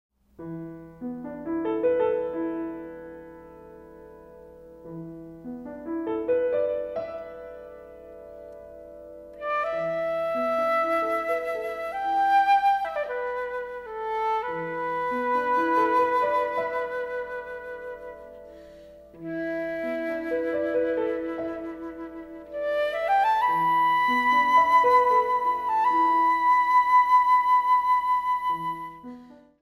このCDは、2005年にカナダのバンクーバーにて収録されたものです。